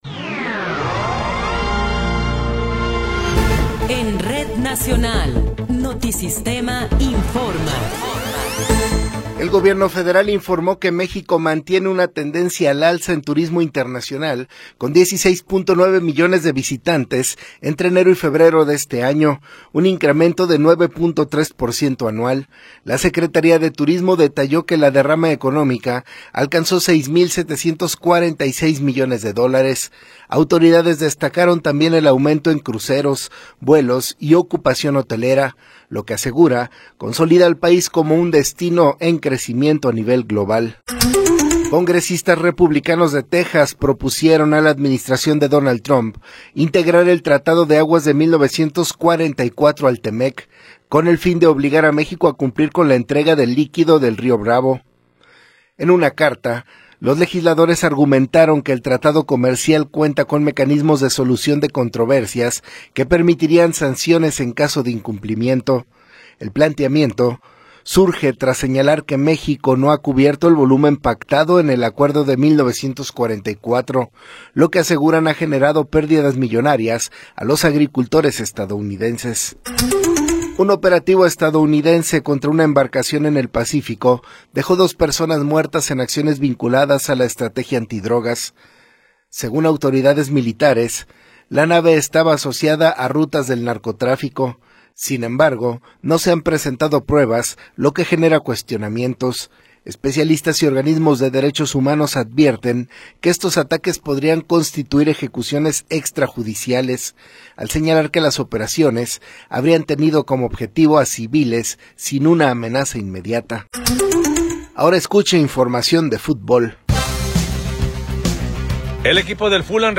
Noticiero 10 hrs. – 25 de Abril de 2026